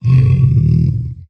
sounds / mob / zoglin / idle1.ogg